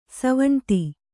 ♪ savaṇti